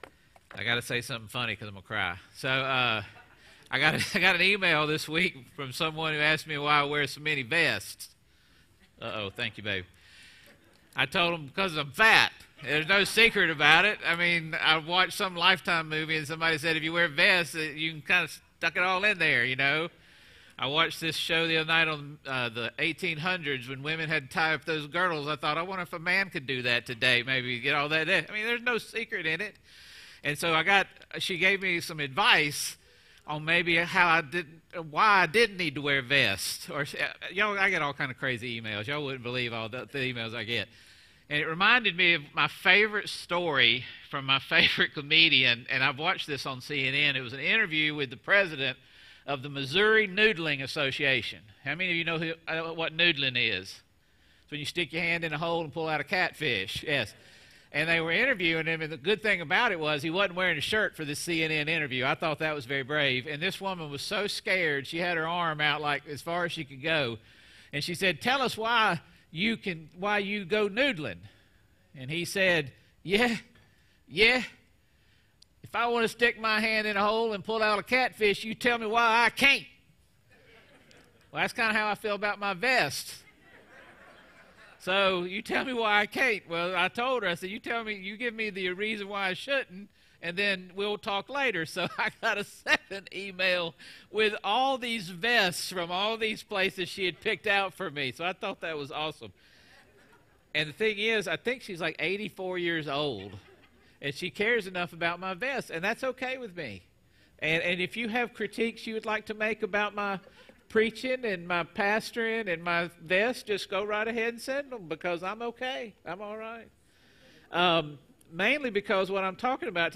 A message from the series "Colossians." The final week of our Colossians series.